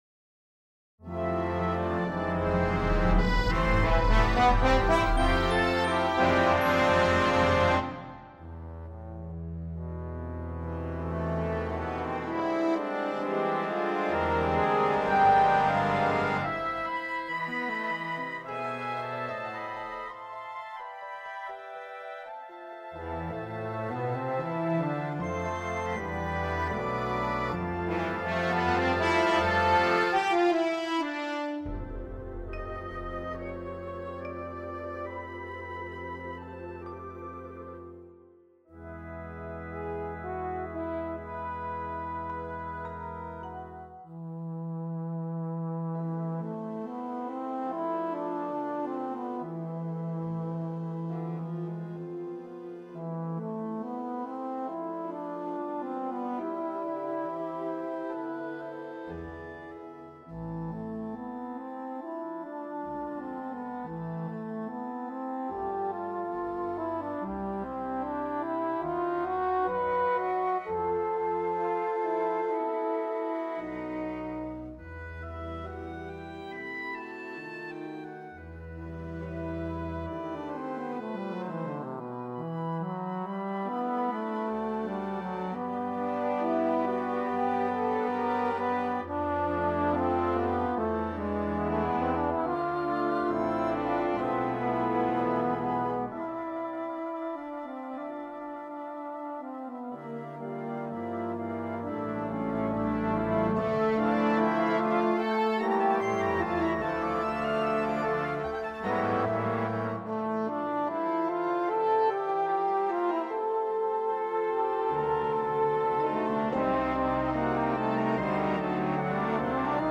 Brass (3 trp., 2 Flg., 4 Hn., 3 Tbn., 2 Euph., Tuba)
Timpani
Percussion I: glockenspiel & vibraphone
Percussion II: Suspended Cymbal, Wind Chimes
Piano